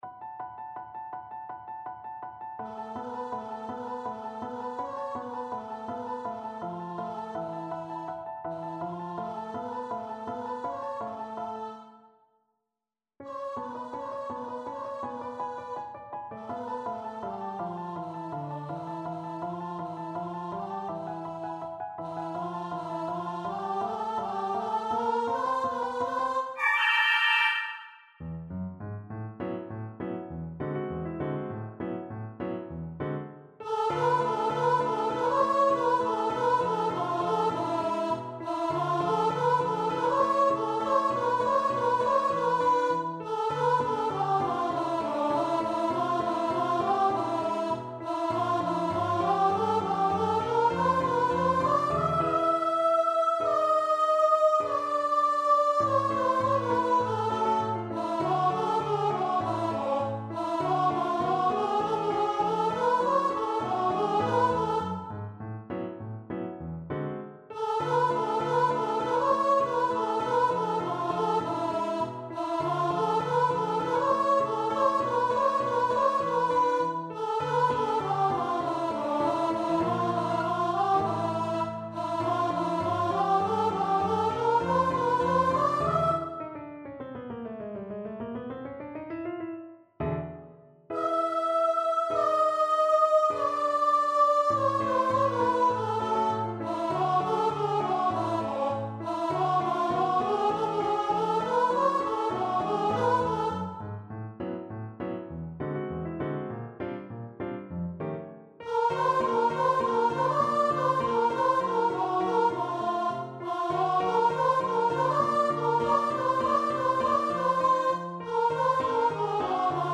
for unison voices and orchestra or piano